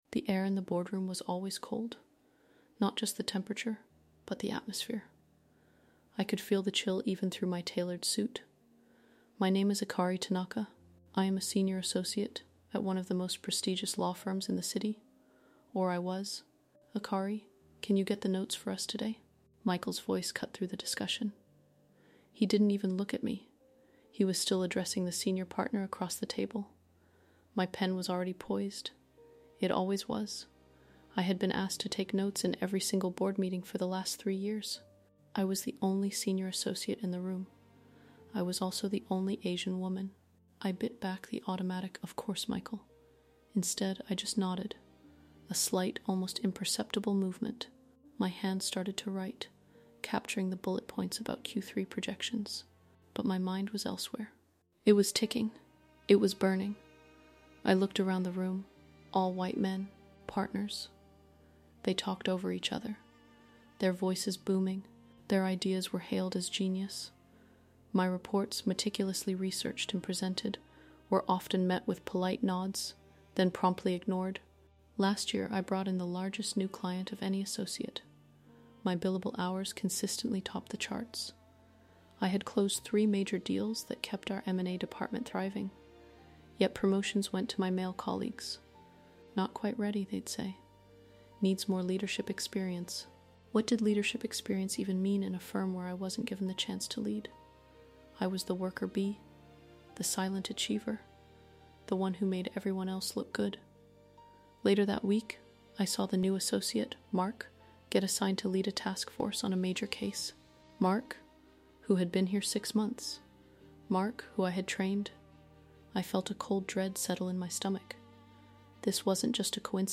This first-person narrative captures the internal friction of a Japanese-American woman forced to choose between the safety of a stable career and the volatile pursuit of justice.